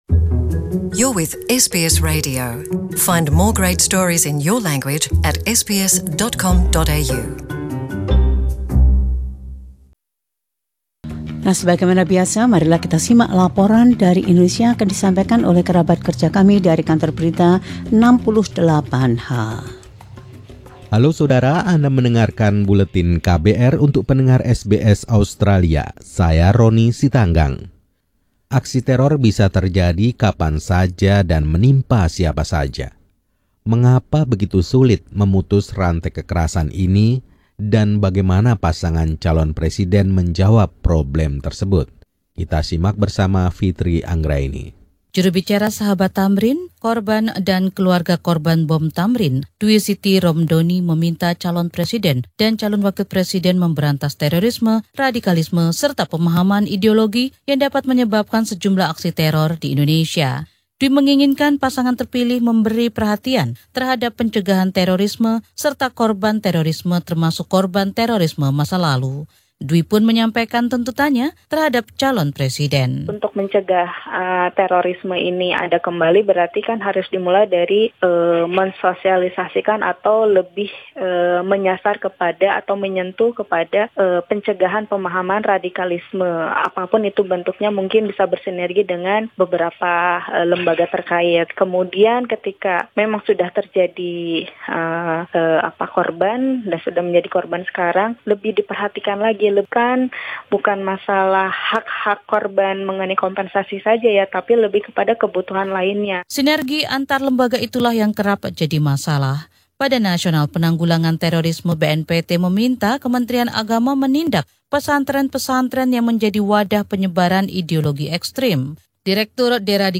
Laporan khusus KBR 68H ini menggambarkan kesenjangan yang ada terkait dengan yang diharapkan.